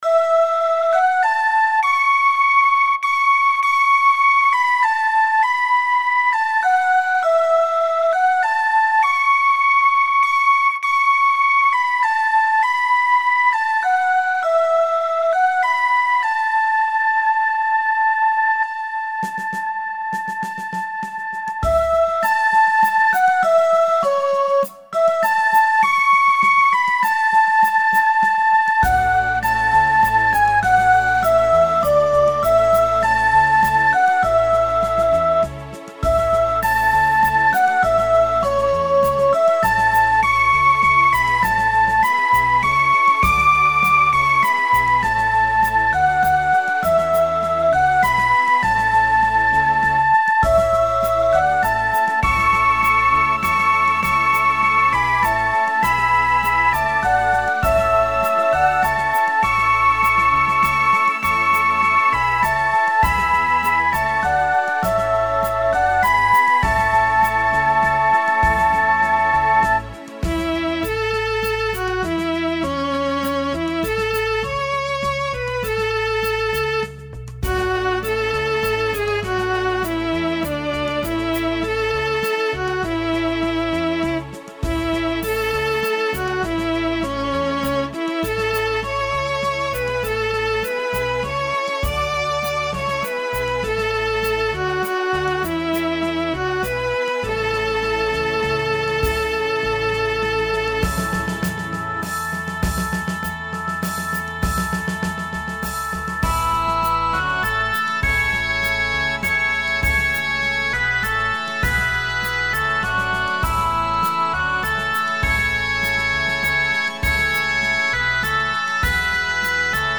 Scottish Folk
Celtic, Folk